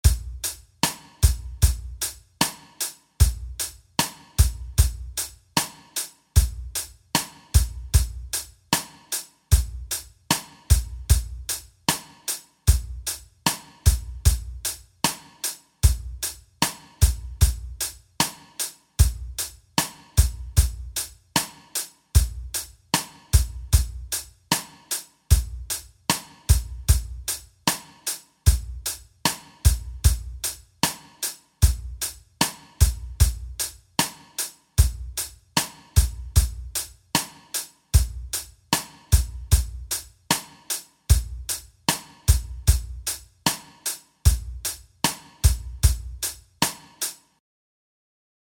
Spend some time playing and listening to this simple 4/4 beat.
• hi-hat
• kick
• snare
Here is the drum loop:
drum-beat.mp3